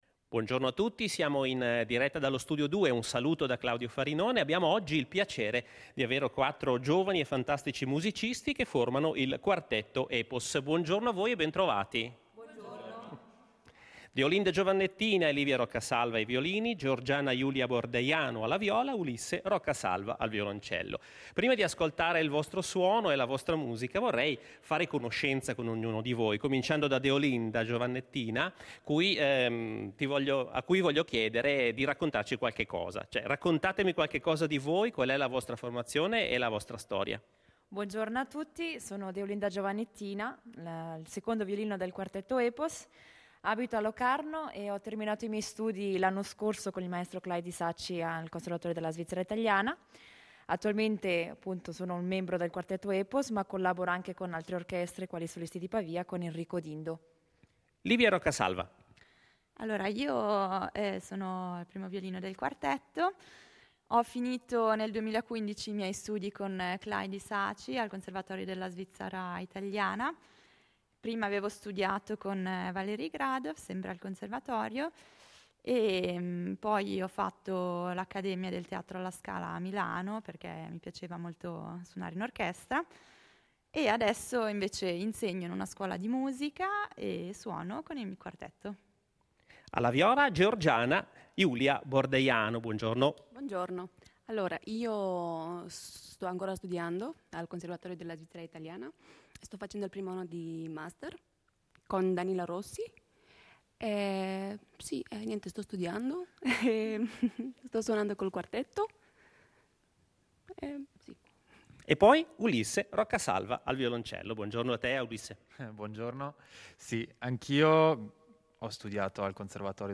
quartetto d’archi
ai violini
alla viola
al violoncello